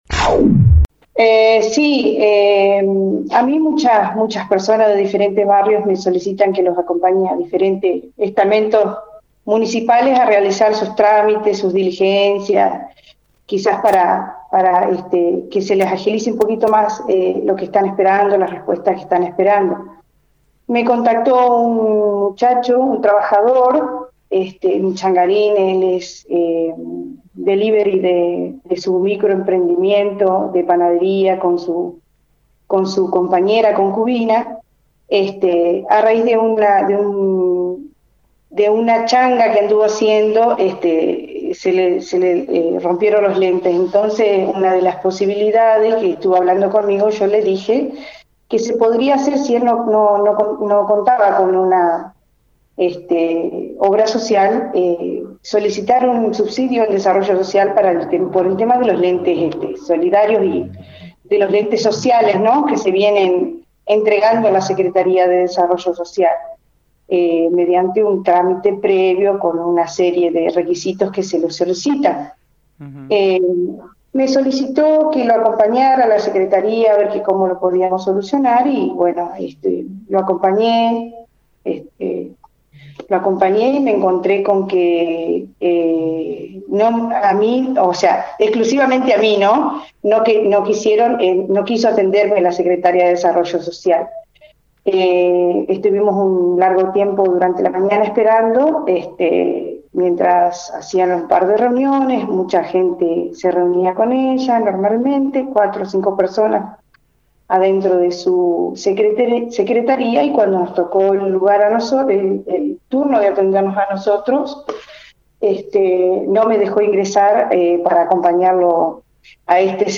Según el relato de la concejal en FM 90.3, luego de una larga espera en la que diversas personas se reunían con la secretaria de Desarrollo Social, toca el turno del hombre, ella se acerca a la oficina y es ahí cuando le niegan la entrada.